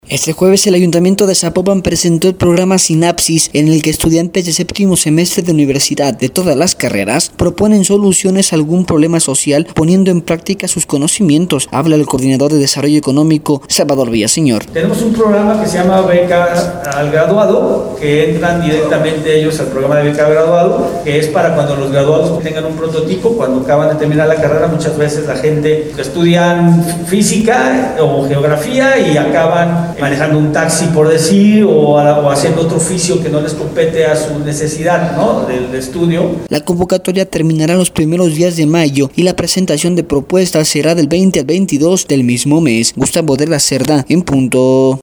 Este jueves el ayuntamiento de Zapopan presentó el programa sinapsis, en el que estudiantes de séptimo semestre de universidad de todas las carreras, proponen soluciones a algún problema social poniendo en práctica sus conocimientos, habla el coordinador de desarrollo económico, Salvador Villaseñor.